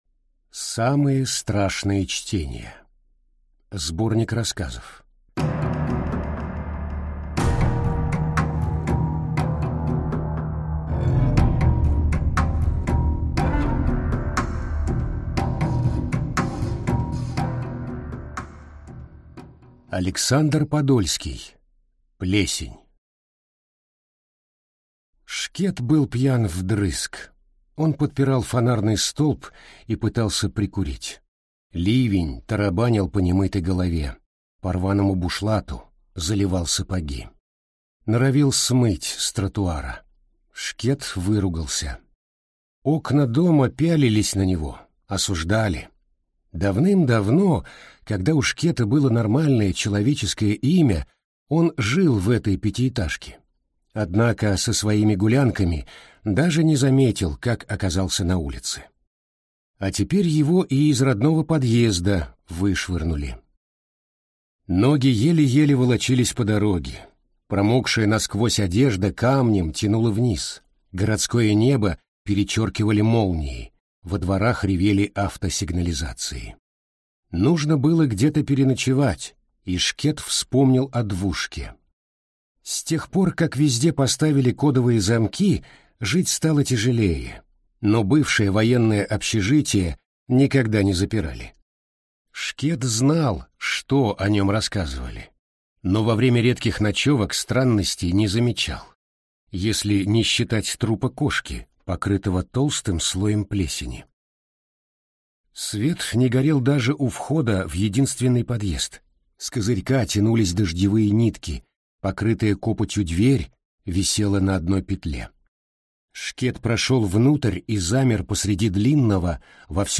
Аудиокнига Самые страшные чтения | Библиотека аудиокниг